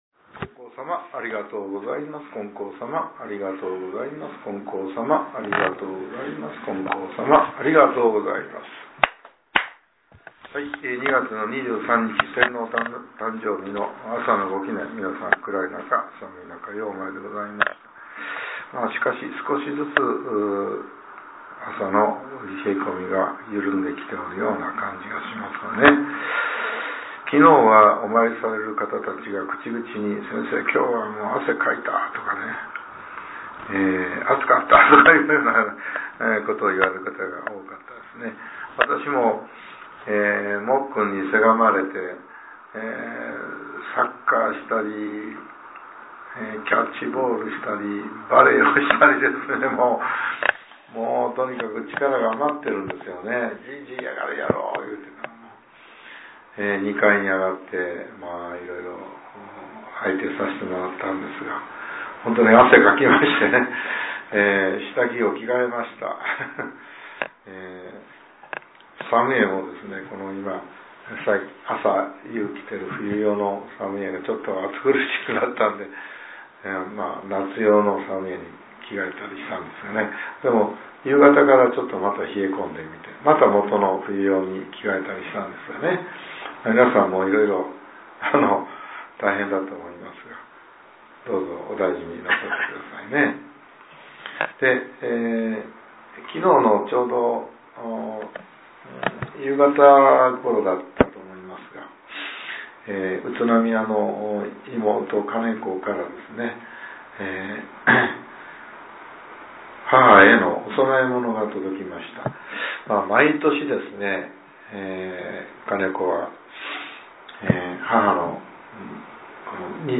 令和８年２月２３日（朝）のお話が、音声ブログとして更新させれています。